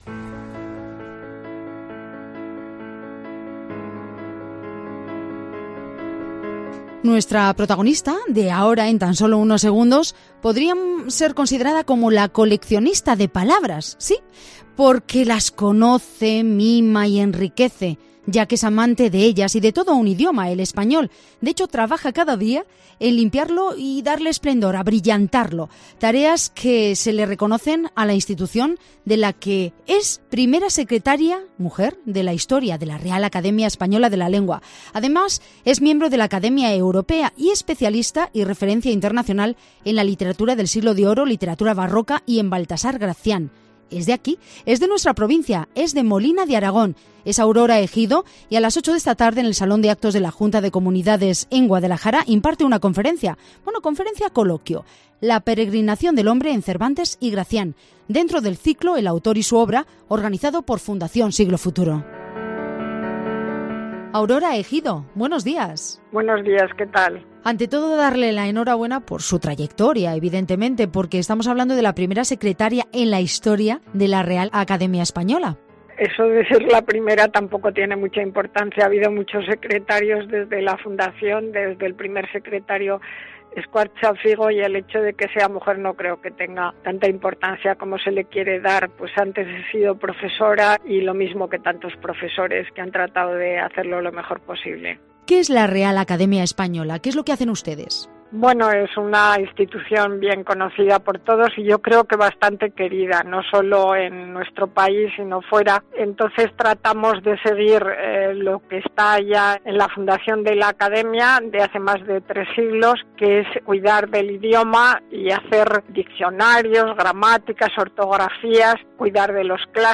Pero además, Egido ha subrayado, en Mediodía Cope Guadalajara, la importante misión que cumple la Real Academia Española, de la que ella misma tiene el honor de ser la primera secretaria de su historia: "es una institución conocida y querida por todos, no sólo en nuestro país sino también fuera, y tratamos de seguir lo que ya está en la fundación de la Academia, de hace ya más de 3 siglos, que es cuidar del idioma, hacer diccionarios, gramáticas, ortografías, cuidar de los clásicos y procurar que se integren en los diccionarios las palabras que el uso va consagrando como propias de nuestro idioma".